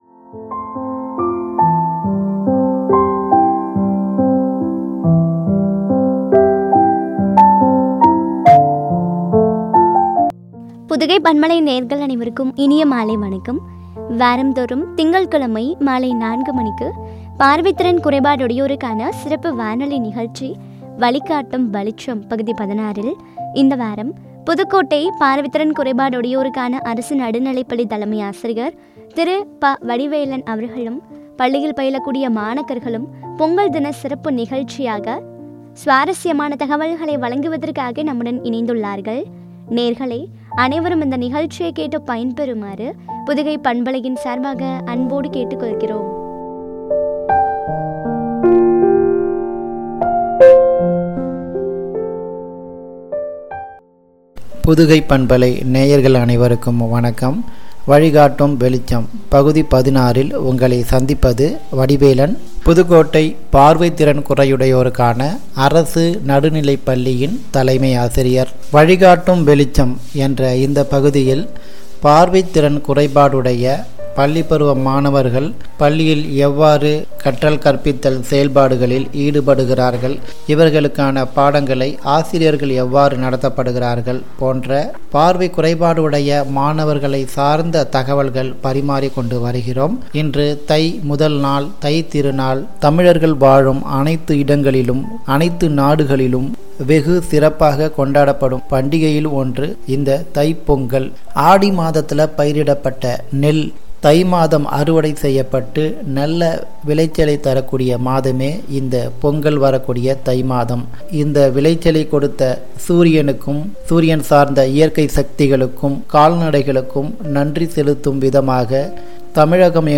” பொங்கல் தின நிகழ்ச்சி குறித்து வழங்கிய உரையாடல்.